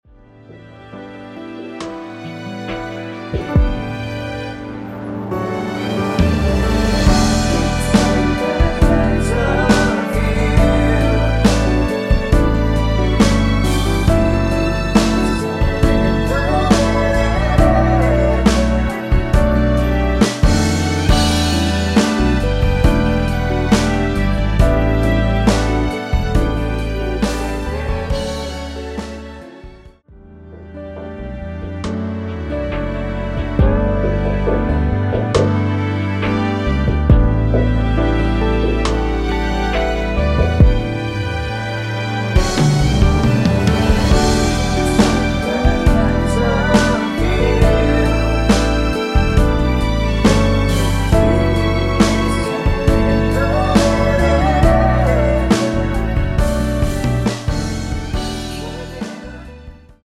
원키에서(-1)내린 코러스 포함된 MR입니다.(미리듣기 확인)
D
앞부분30초, 뒷부분30초씩 편집해서 올려 드리고 있습니다.